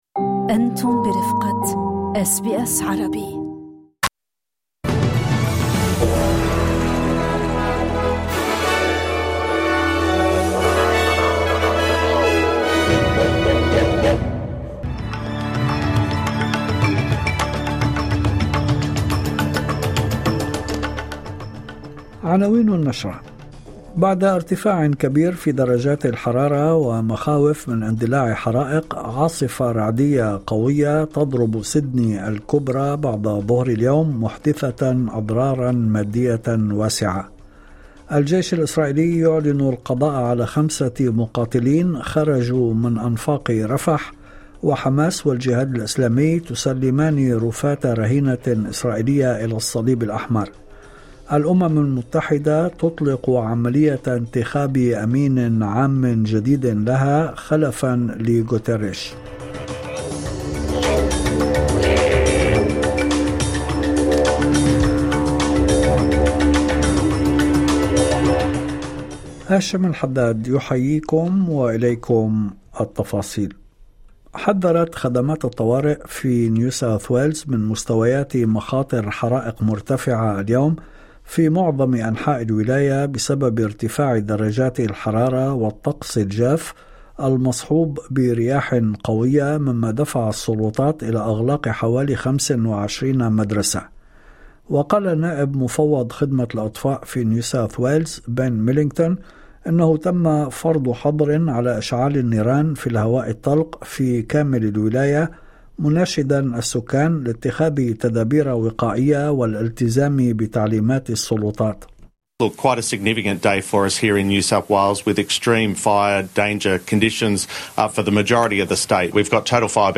نشرة أخبار المساء 26/11/2025